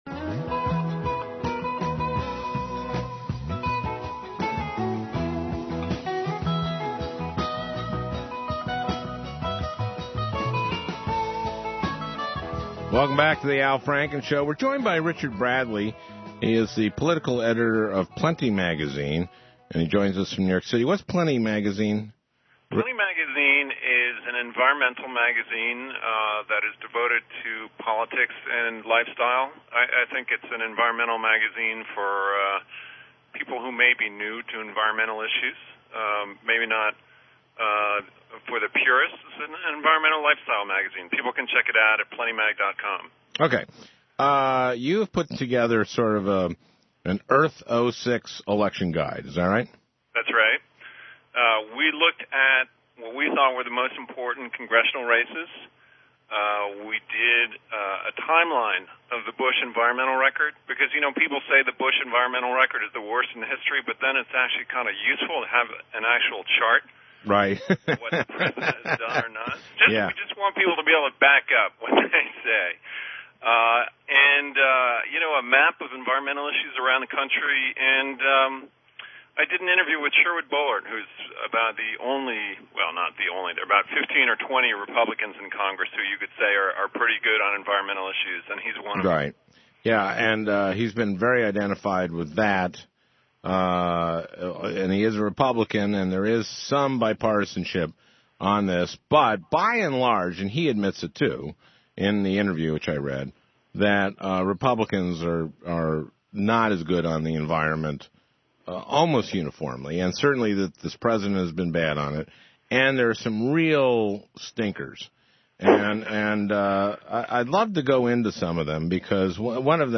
Chatting with Al Franken If you're interested in hearing me talk with Al Franken about the 2006 election, here's the audio from my Air America interview.....